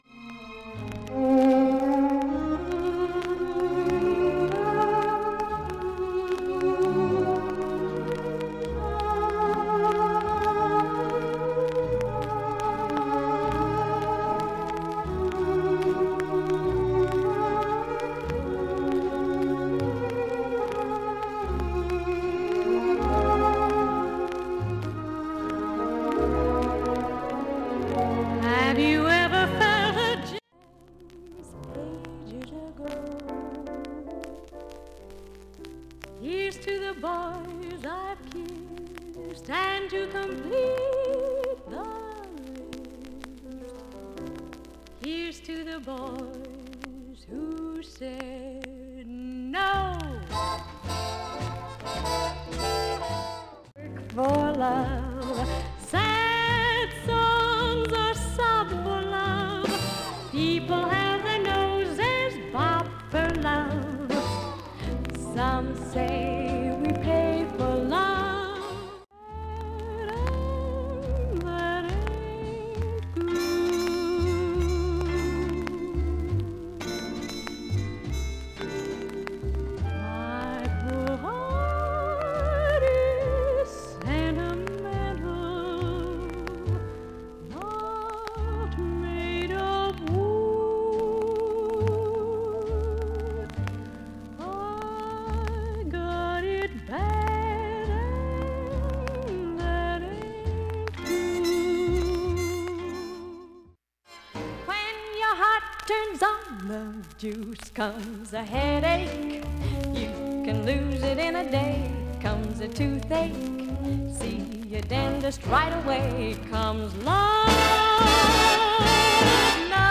主にプツ箇所を試聴にてダイジェストで聴けます。